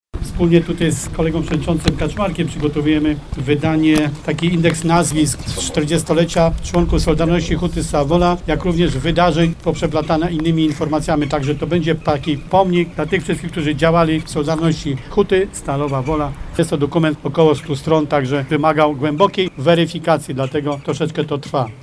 Poinformował o tym podczas uroczystości rocznicowej pod Krzyżem przy Narzędziowni